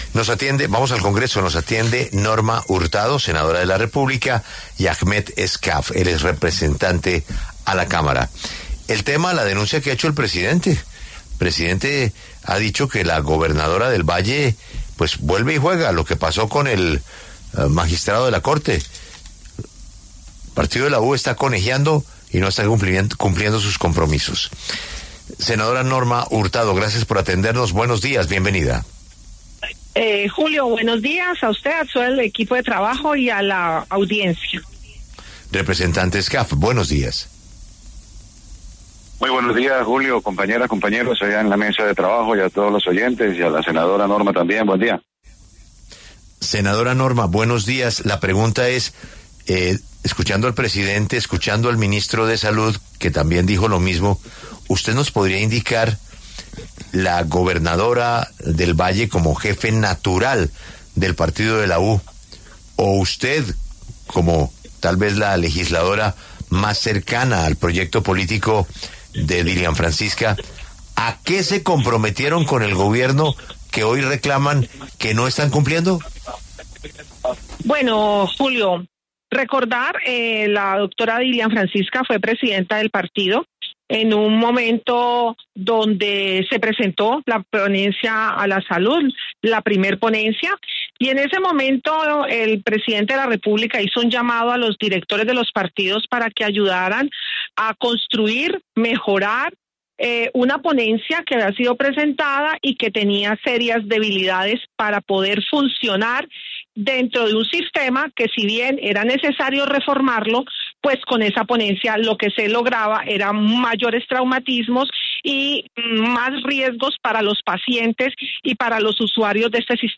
La senadora Norma Hurtado, del Partido de La U, respondió en La W al ministro de Salud, Guillermo Alfonso Jaramillo. También hablo sobre el tema el representante Agmeth Escaf, del Pacto Histórico, quien estuvo en los diálogos con los partidos tradicionales.